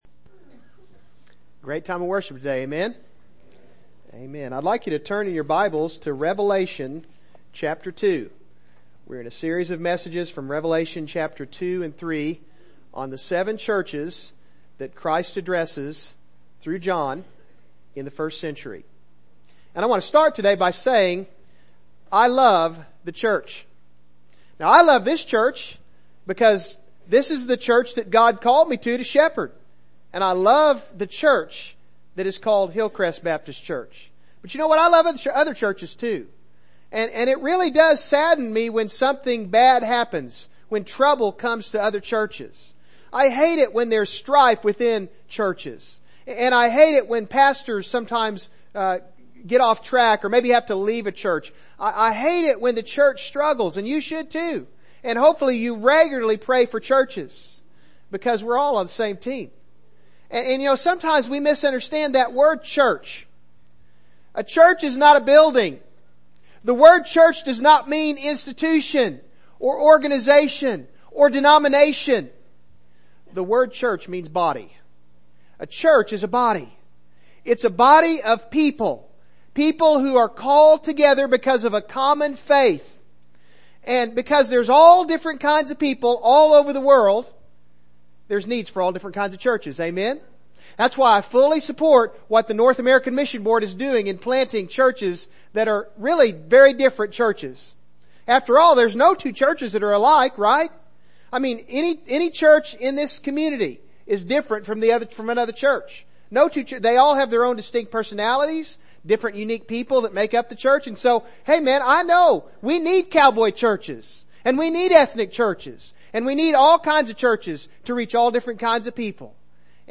Passage: Revelation 2:12-17 Service Type: Morning Service